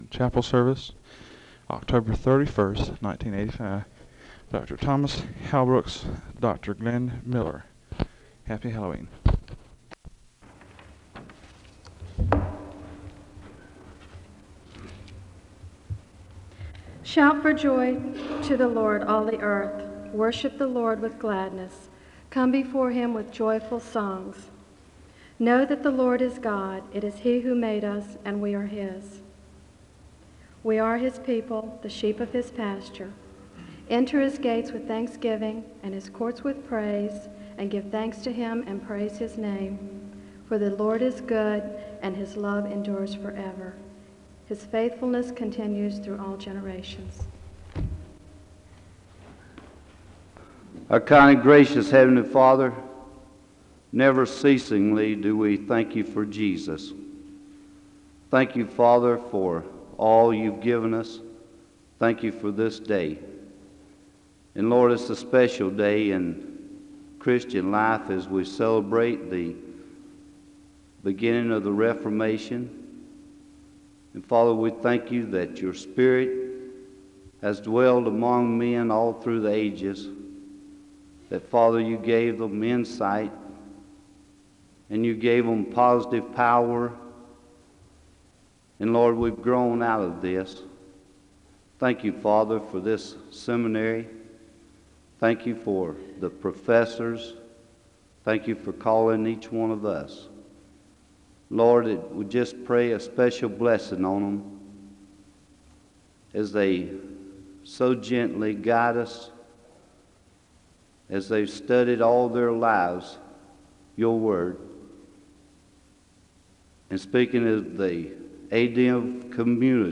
The service begins with a Scripture reading from the Psalms and a word of prayer (00:00-03:10). The speaker delivers the Scripture reading from John 3:1-10, and the audience is led in a church history litany (03:11-06:08).
Southeastern Baptist Theological Seminary